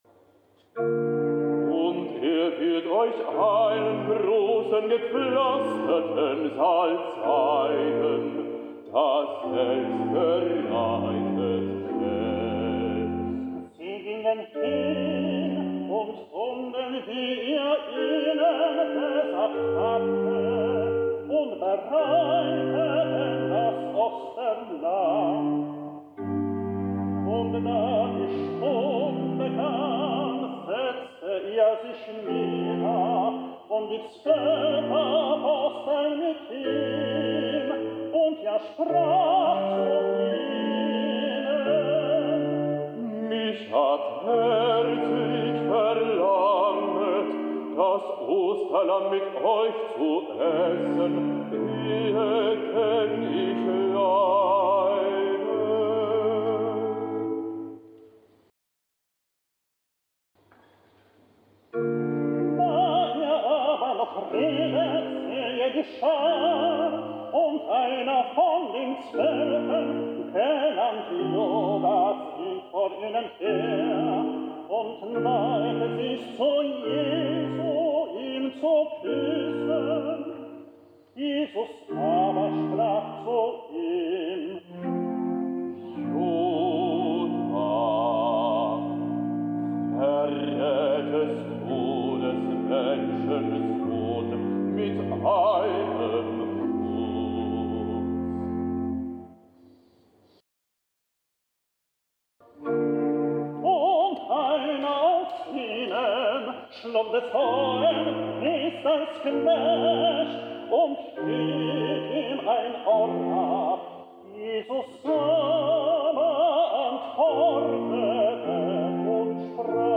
2023-04-02 J. S. Bach / Anonym - Lukaspassion BWV 246 (Pasja wg św. Łukasza) / Tenor Solo
2 kwietnia 2023 roku (w Niedzielę Palmową) w Kościele Ewangelickim w Osterfeld (Niemcy) odbył się koncert, w programie którego znalazła się Pasja wg św. Łukasza (Lukaspassion).